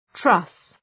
Προφορά
{trʌs}